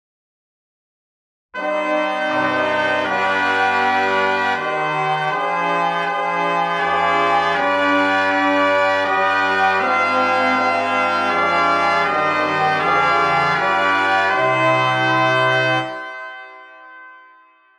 音源は、比較のために、全て金管にしています。